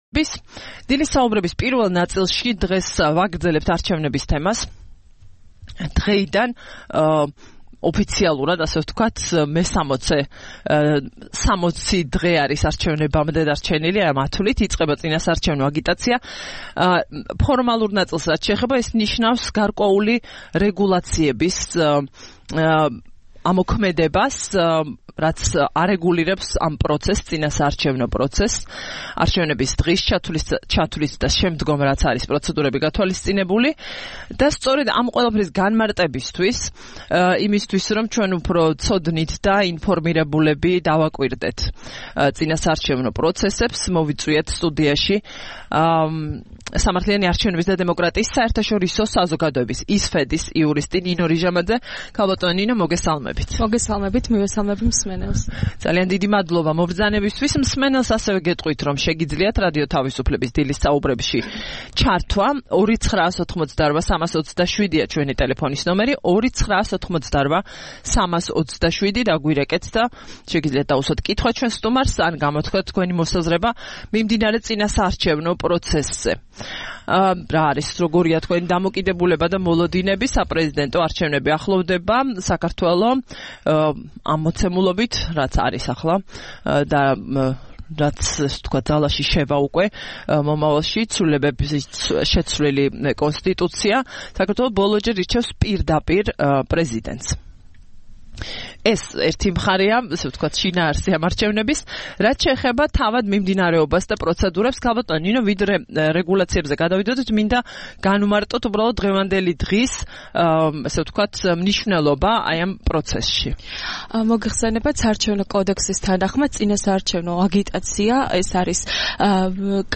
რადიო თავისუფლების "დილის საუბრების" სტუმარი იყო